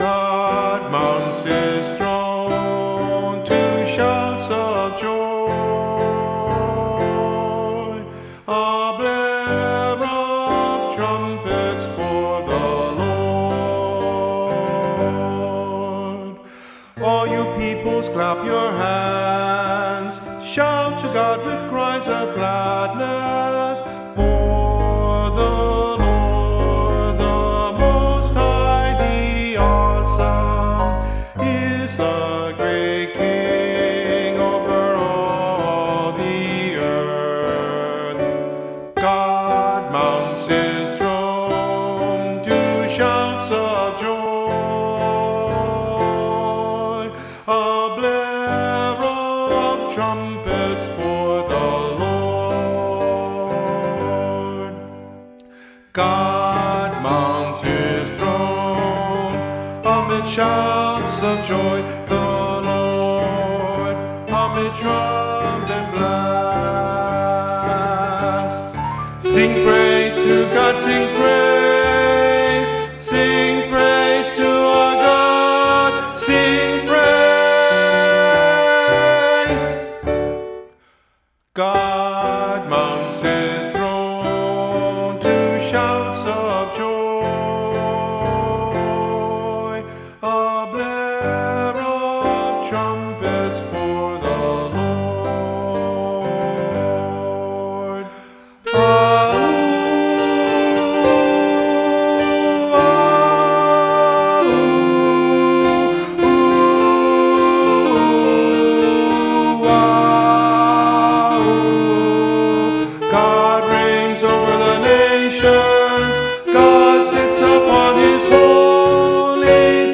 Soprano   Instrumental | Downloadable   Voice | Downloadable